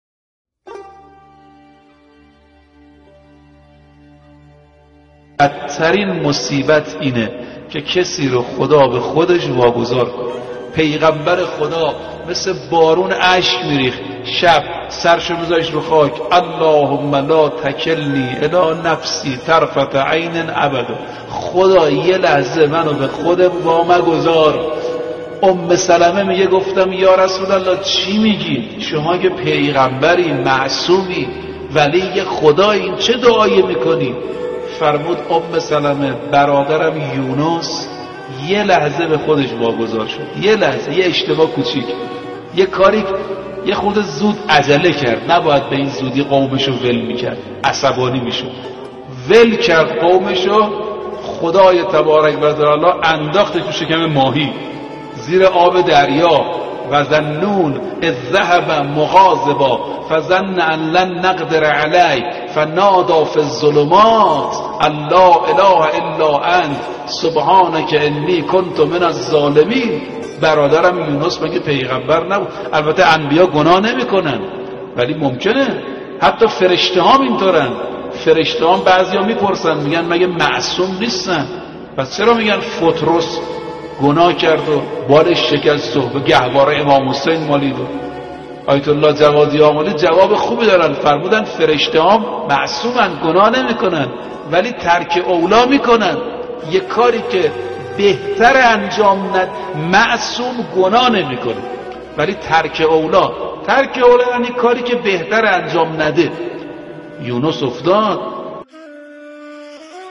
سخنرانی | بدترین مصیبت برای انسان واگذار کردن او به خودش است
سخنرانی حجت الاسلام